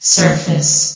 S.P.L.U.R.T-Station-13 / sound / vox_fem / surface.ogg
CitadelStationBot df15bbe0f0 [MIRROR] New & Fixed AI VOX Sound Files ( #6003 ) ...